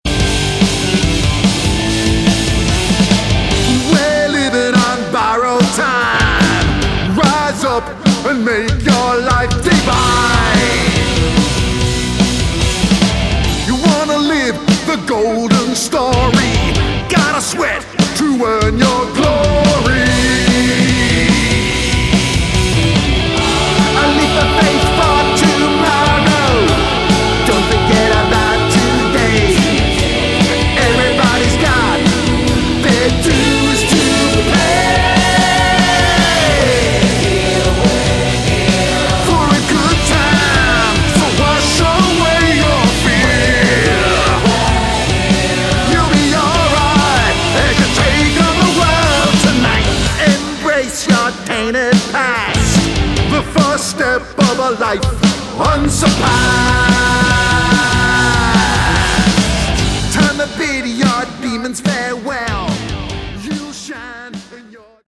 Category: Melodic Metal
vocals, keyboards, guitars
rhythm and lead guitars
backing vocals